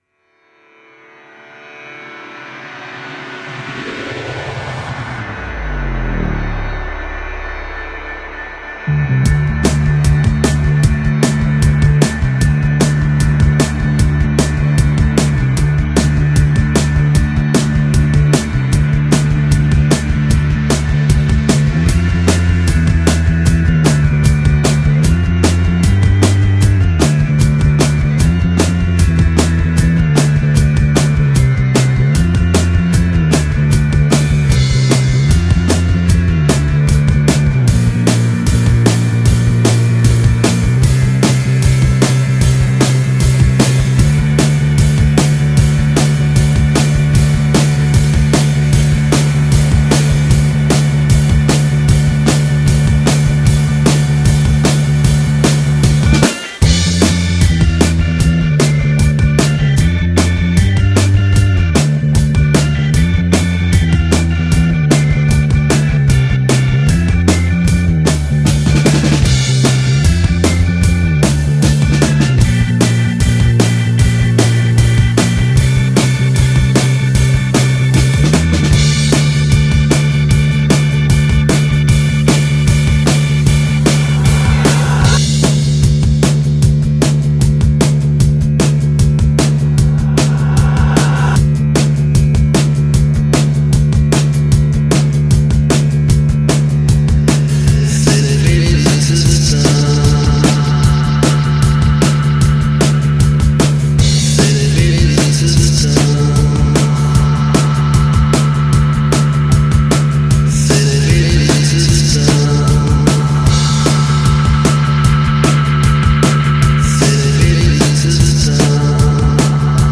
A great psychedelic song